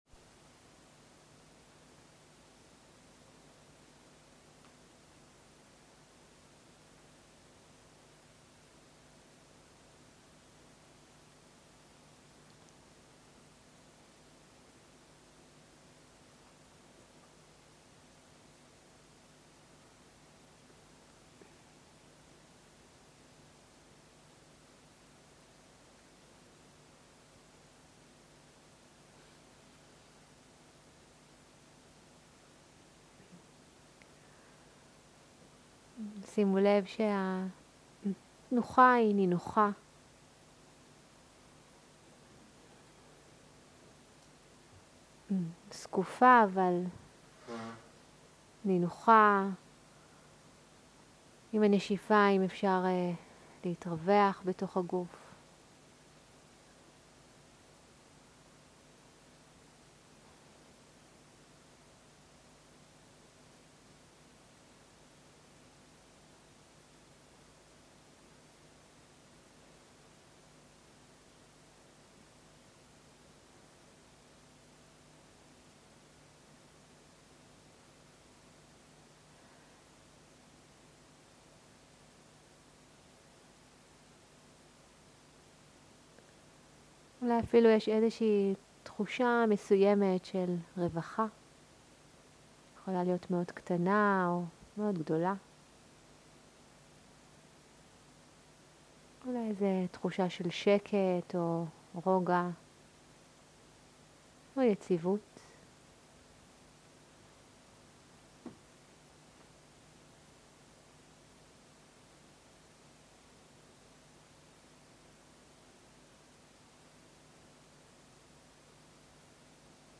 מדיטציה מונחית - ברהמה ויהרס ועוד
סוג ההקלטה: מדיטציה מונחית